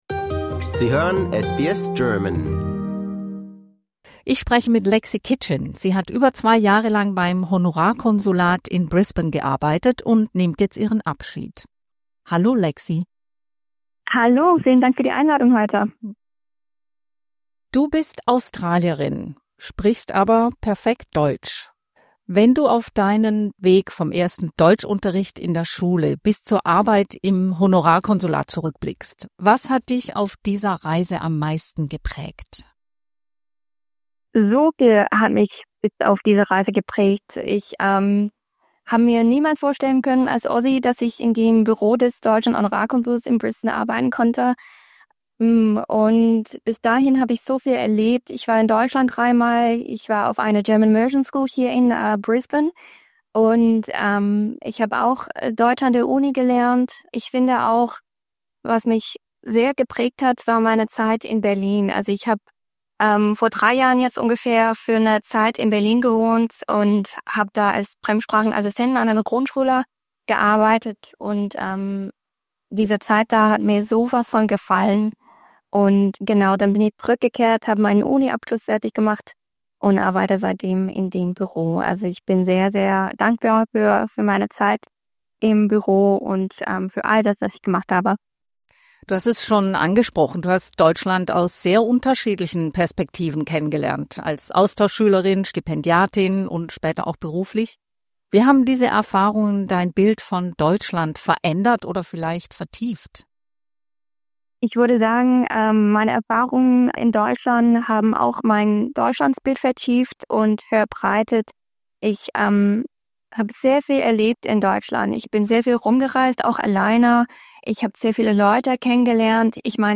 Im Interview spricht sie über ihre Erfahrungen, prägende Momente und besondere Höhepunkte.